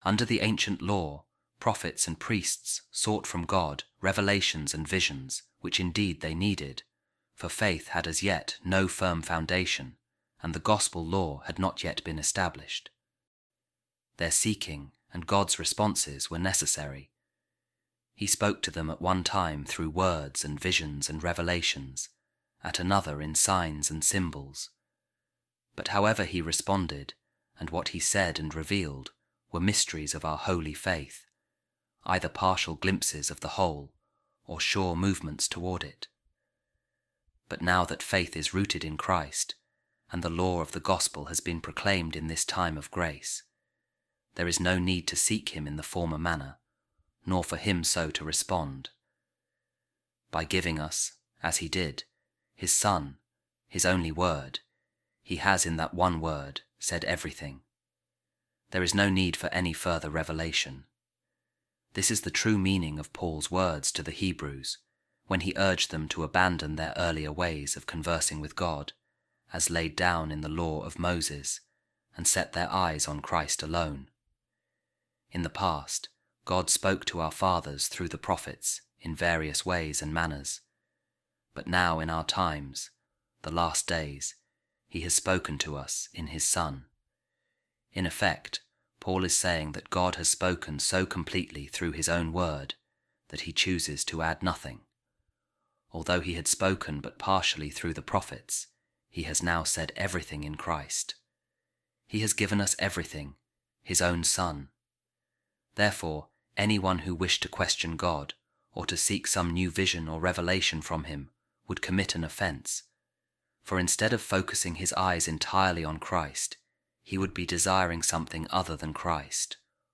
Office Of Readings | Advent Monday Week 2 | A Reading From The Ascent Of Mount Carmel By Saint John Of The Cross | God Has Spoken To Us In Christ